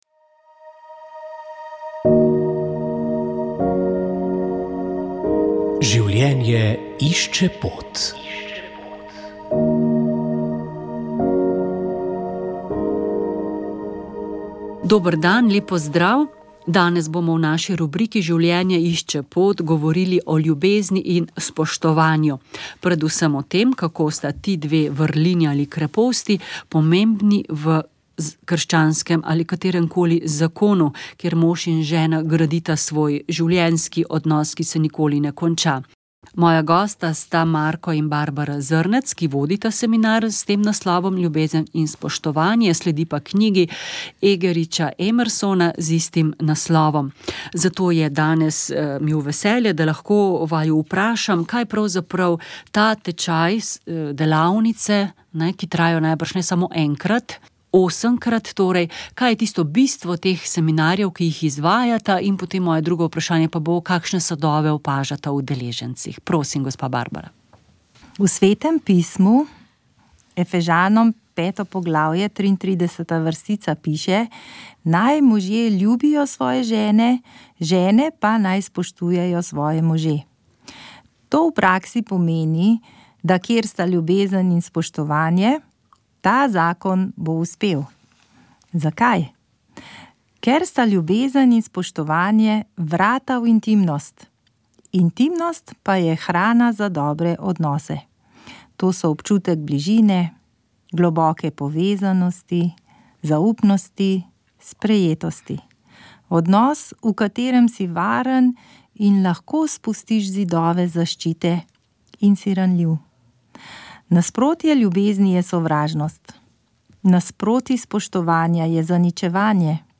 V drugem delu oddaje pa smo slišali nekaj uvodnih misli iz daljšega intervjuja